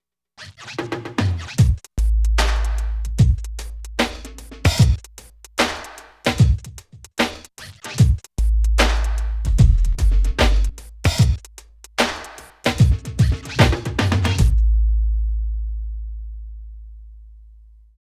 108 LOOP  -R.wav